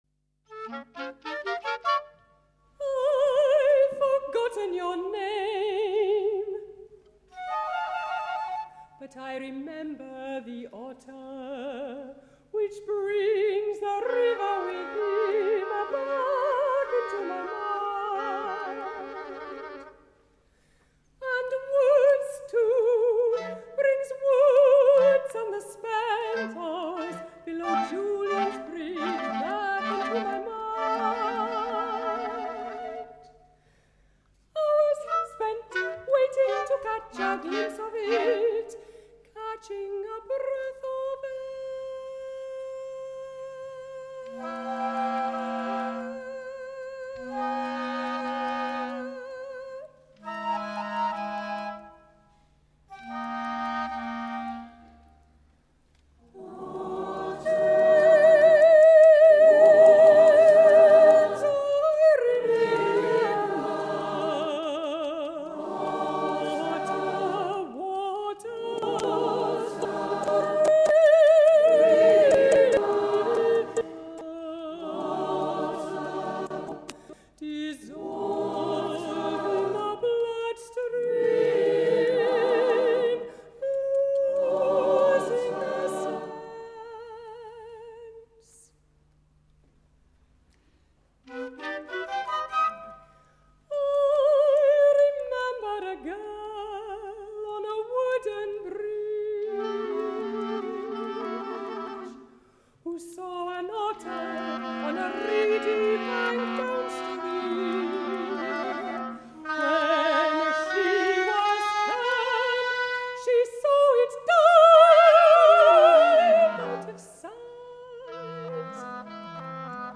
WCT joined other singers to perform the choral works as part of Otter – Lutra, Lutra on the Stour, in Wimborne Minster and Guildford Cathedral.